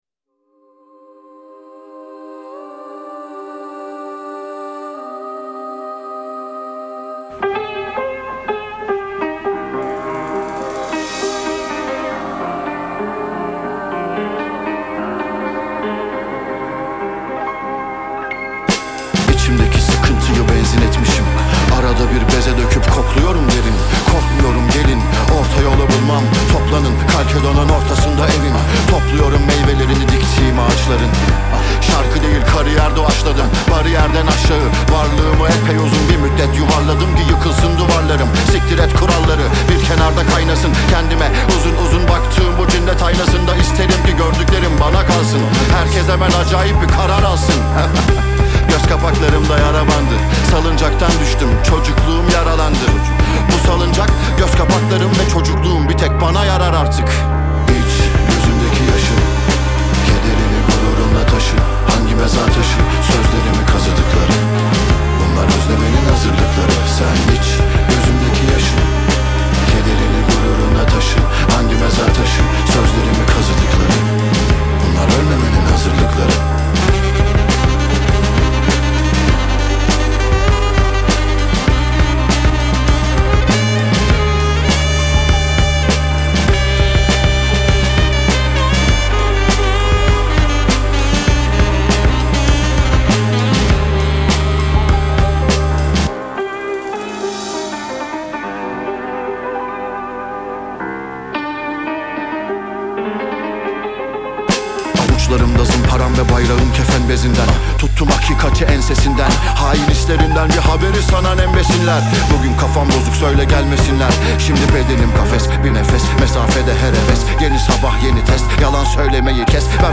Трек размещён в разделе Турецкая музыка / Рэп и хип-хоп.